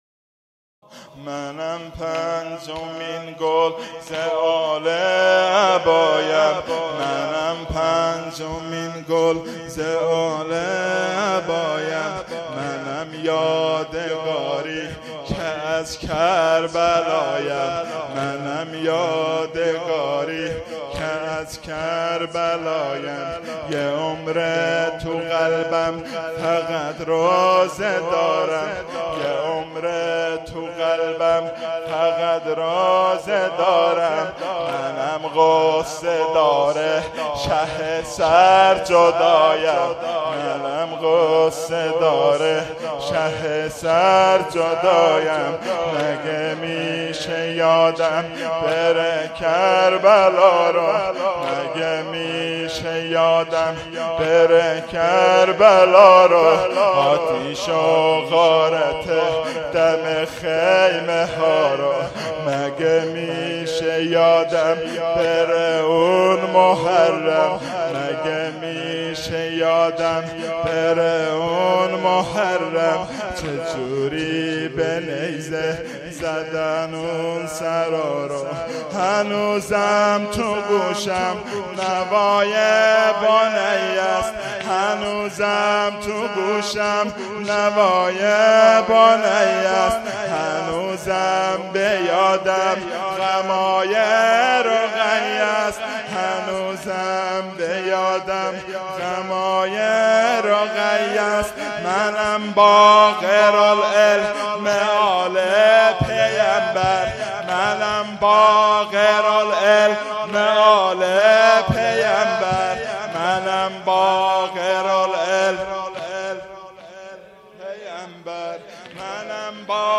هیئت و کانون منتظران موعود
واحد شلاقی|منم پنجمین گل ز آل عبایم